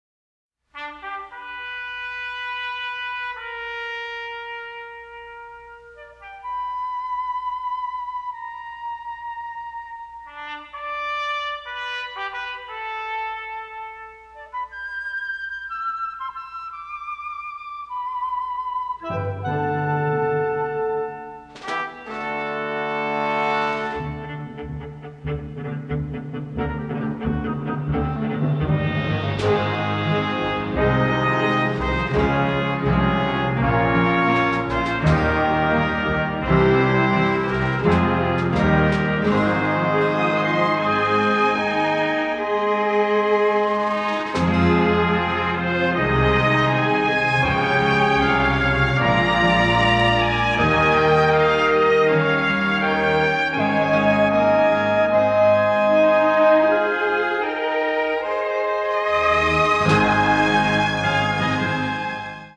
synthesizers morphed with traditional orchestral instruments